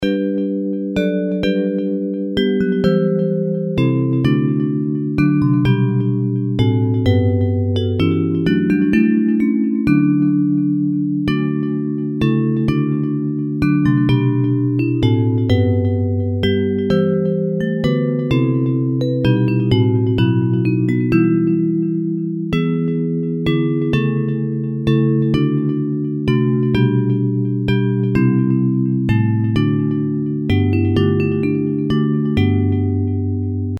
Hymn lyrics and .mp3 Download
Bells Version